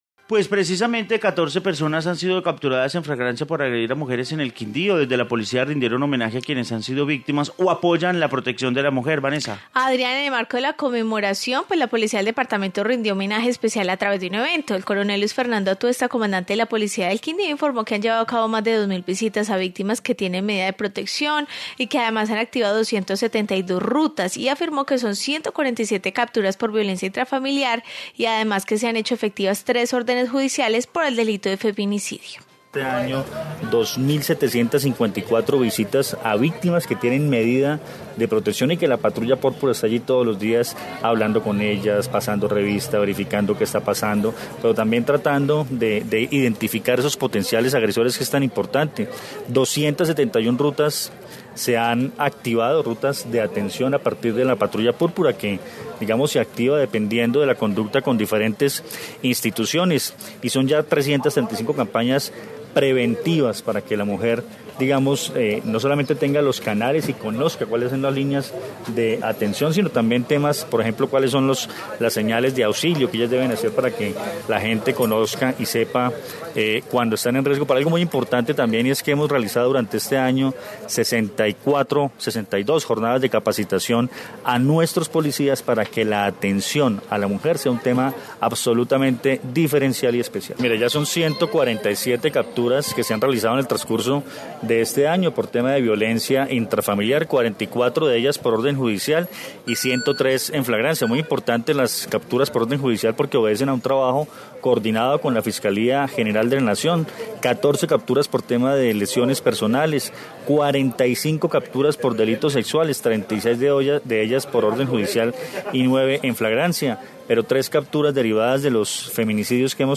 Informe sobre conmemoración